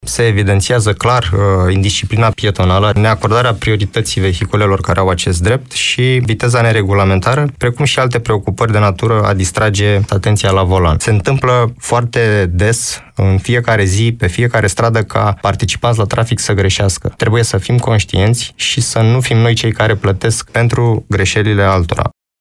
Invitat marți, în emisiunea Dialoguri la zi, de la Radio Constanța